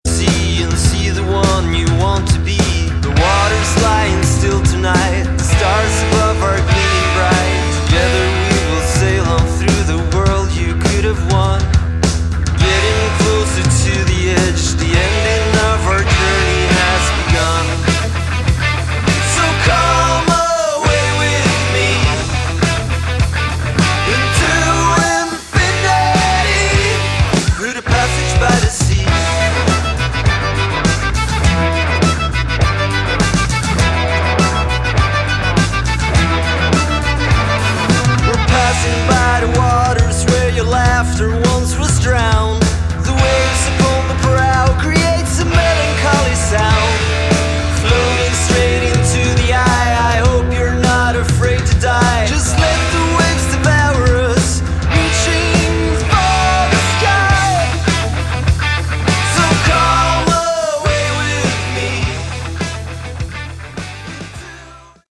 Category: Hard Rock
guitar, vocals
drums